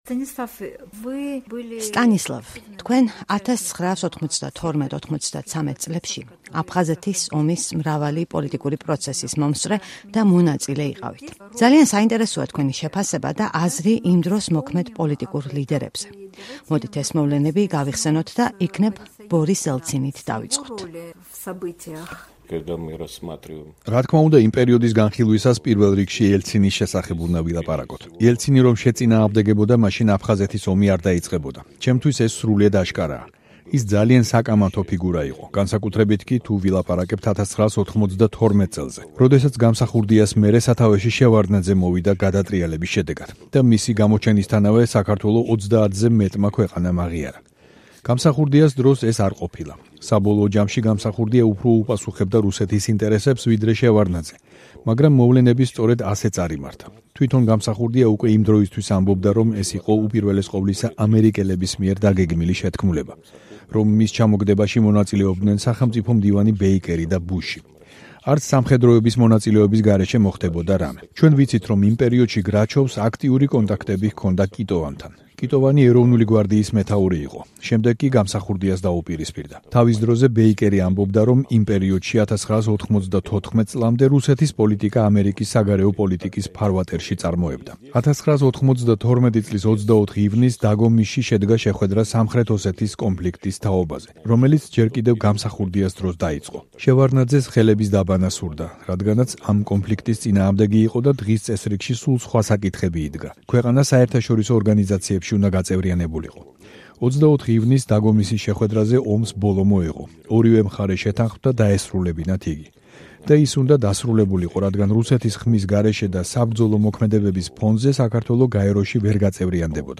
ინტერვიუ სტანისლავ ლაკობასთან